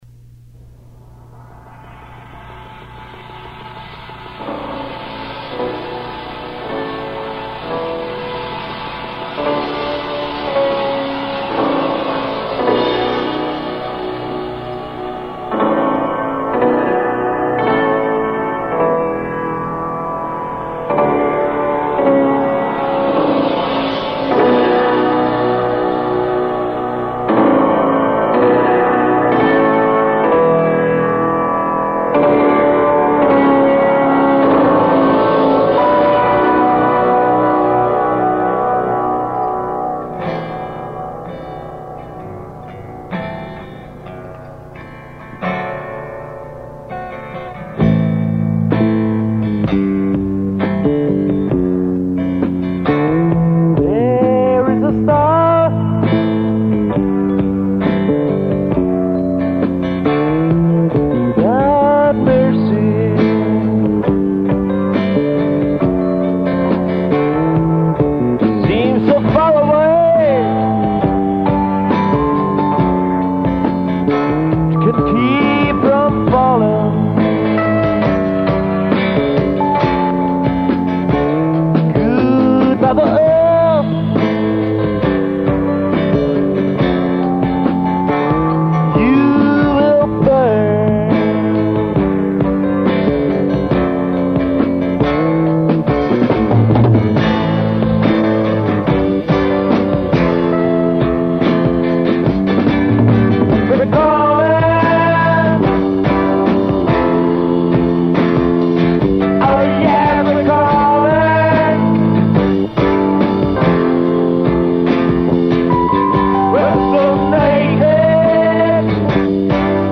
voc. e-guit.
keyb.
bass
drums